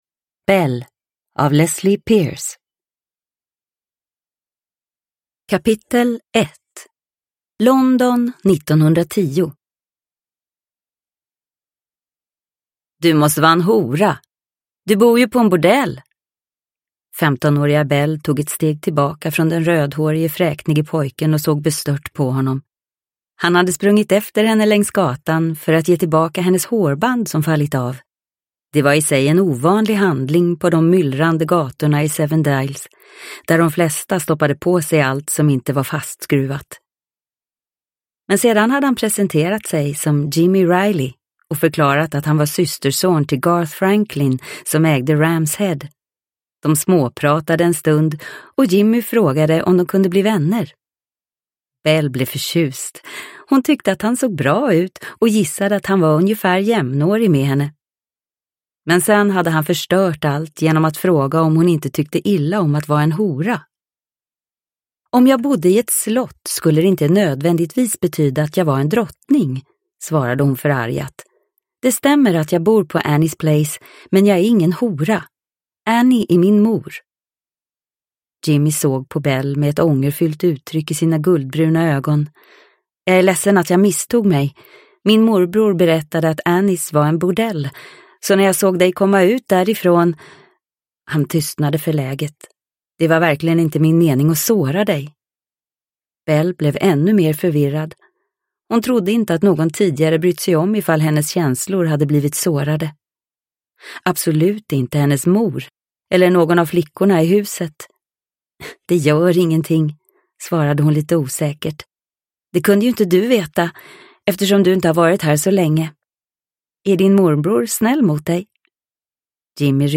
Belle – Ljudbok – Laddas ner